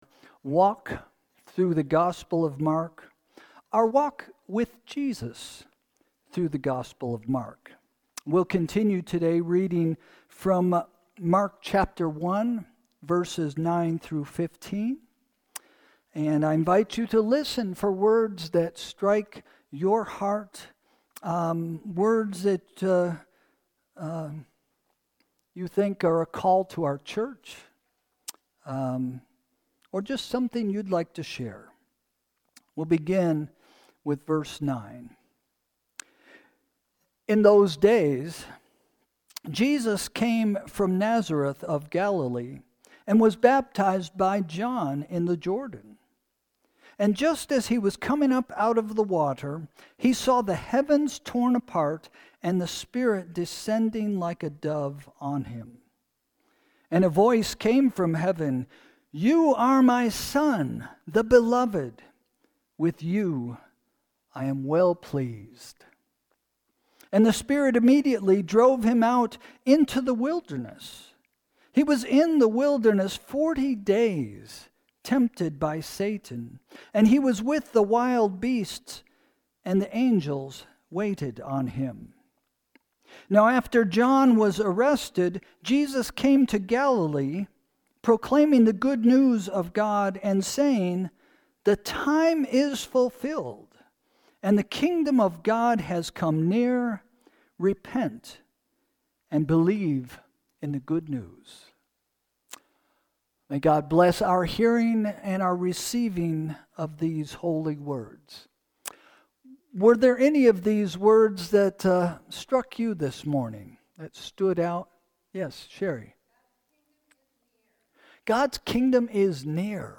Sermon – October 19, 2025 – “Even in Your Wilderness” – First Christian Church